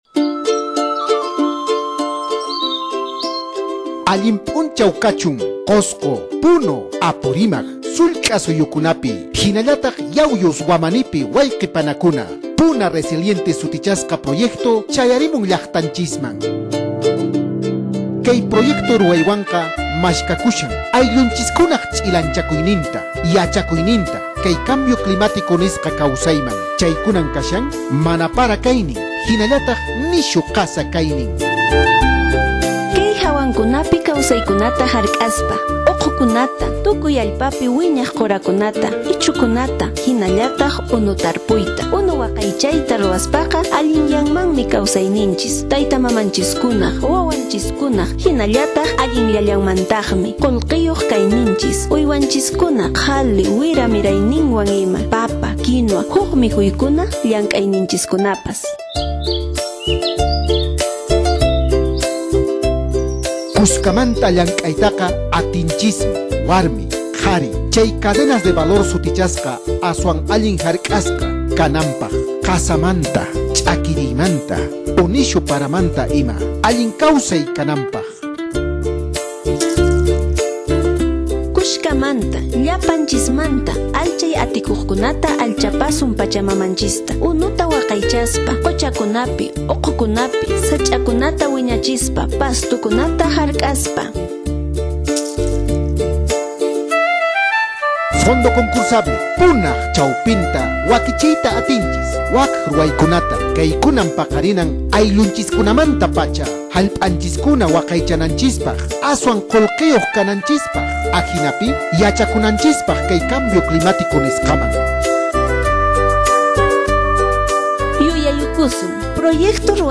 Cuña radial sobre el proyecto Puna Resiliente